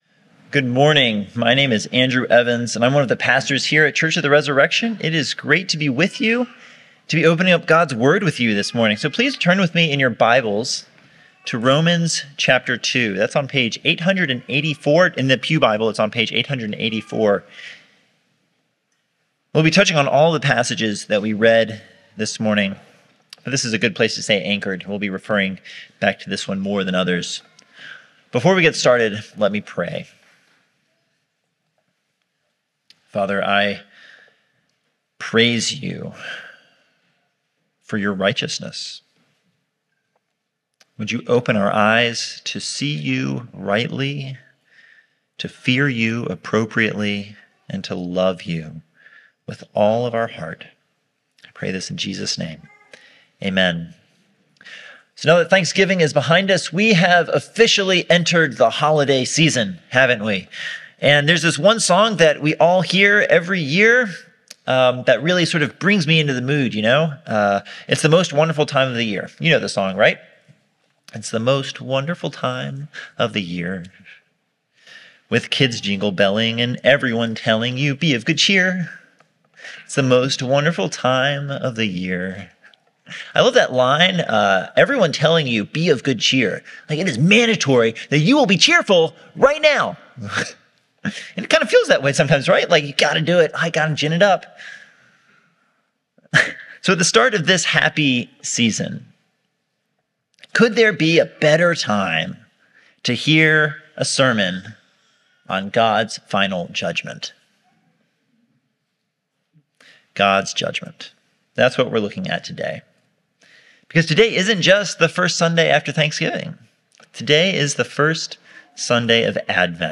Advent 2024 Passage: Psalm 50, Romans 2:3-16 Service Type: Sunday Service « The Joseph Story